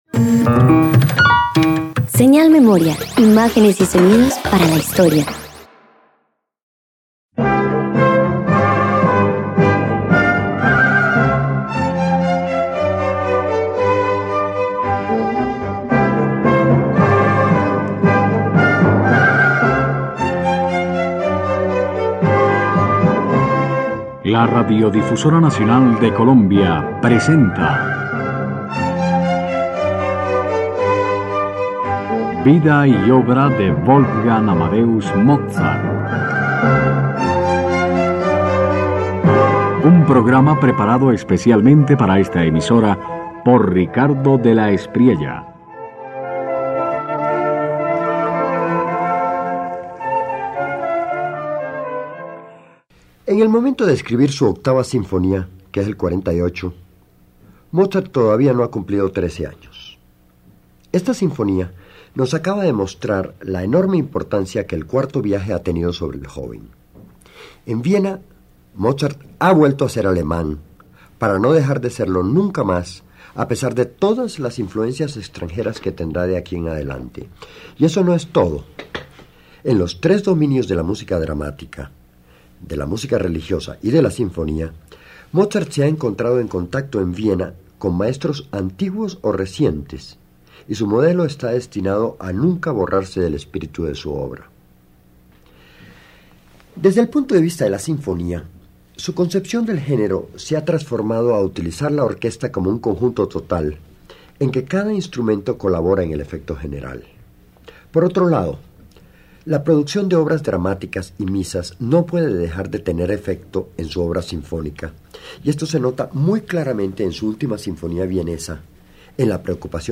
En la sinfonía No. 8, Mozart organiza con frescura juvenil una orquesta que ya no es solo una suma de timbres, sino un organismo en el que cada sección dialoga con soltura, dando a la forma un aire ligero, pero lleno de inteligencia.
028 La Sinfoni╠üa No 8  y sonatas para claveci╠ün y violi╠ün_1.mp3